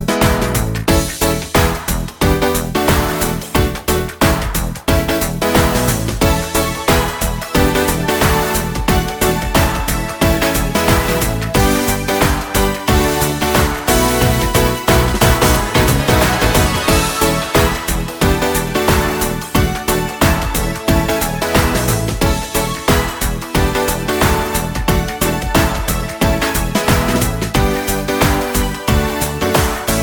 Musicals
Ein Musical für Kids & Teens